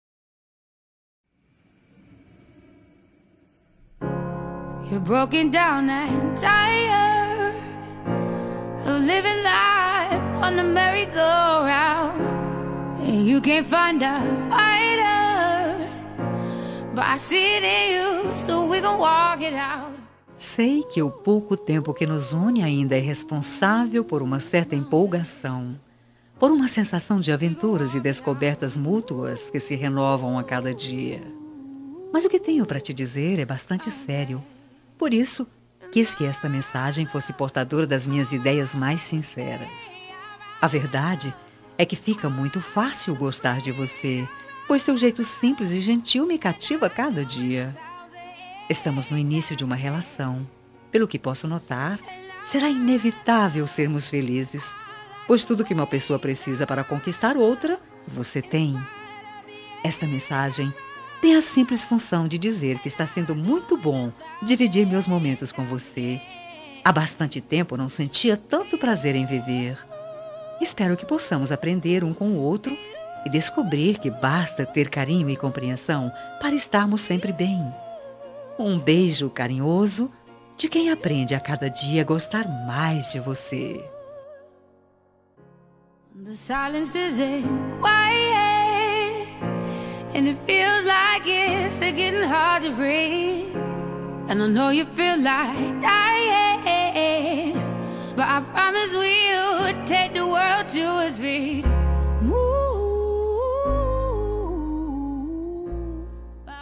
Telemensagem Para Ficante – Voz Feminina – Cód: 5427